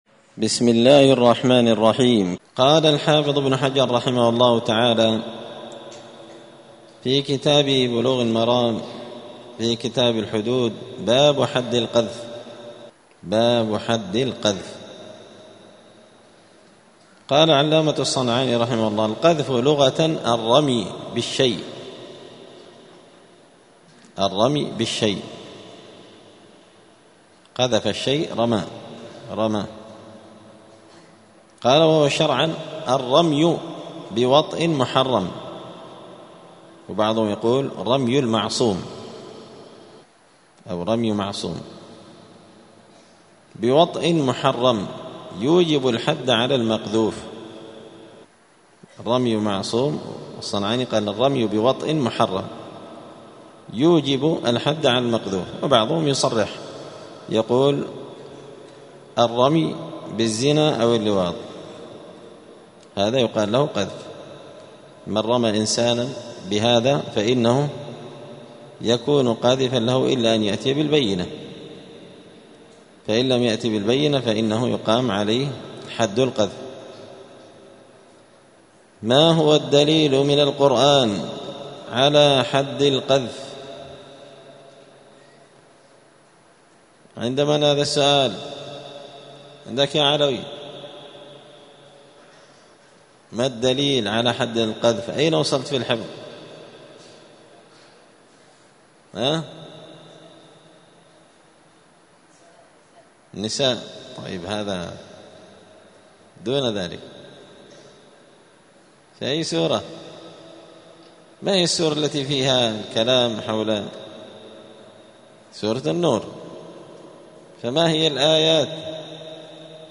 *الدرس الرابع عشر (14) {باب حد القذف}*